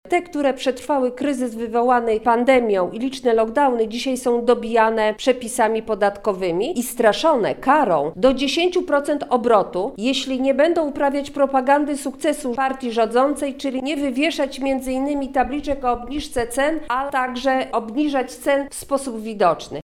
Te przepisy uderzają w każdego obywatela, ale także mikro, małe i średnie firmy – mówi Posłanka Koalicji Obywatelskiej, Marta Wcisło.
Posłanka, podczas konferencji zapytała dlaczego, przykładowo chleb nie staniał o przynajmniej o złotówkę, tak jak zapowiadał rząd.